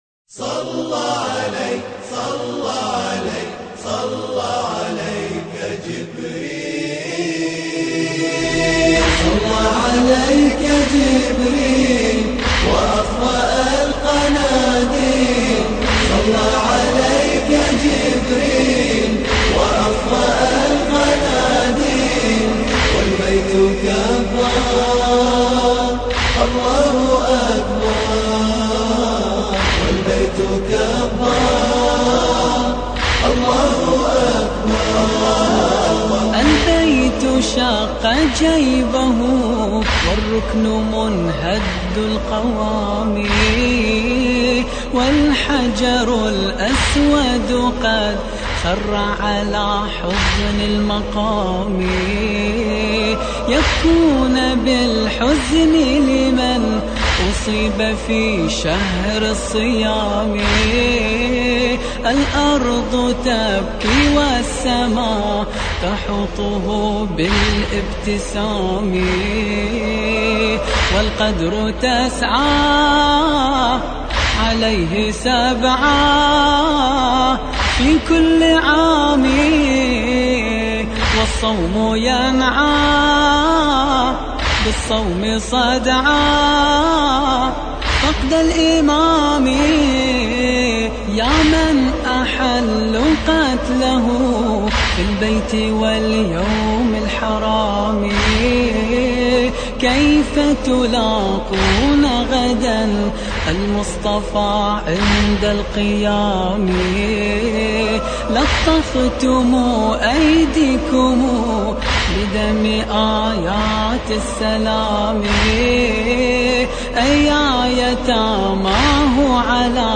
مراثي الامام علي (ع)